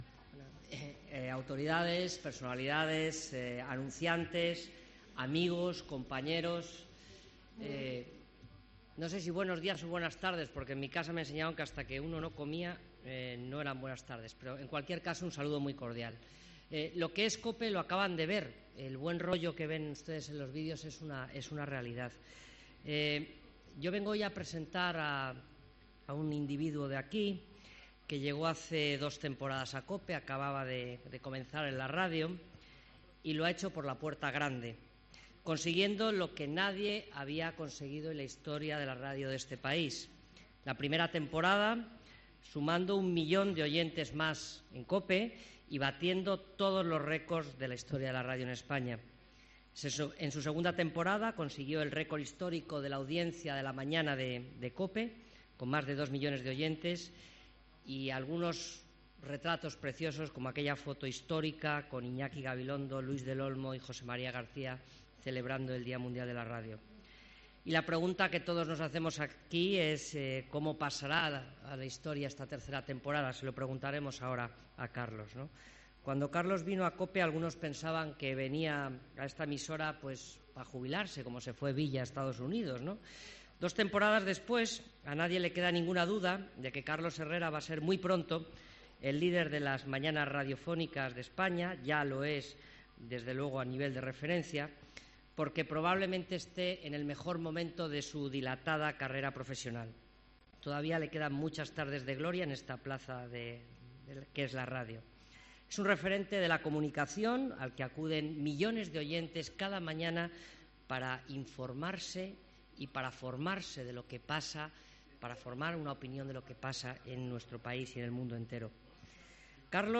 Presentación nueva temporada COPE Sevilla